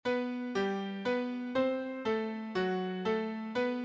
• Inversion flips a melody upside-down.
Pitch class sequence B G B C A F# A B.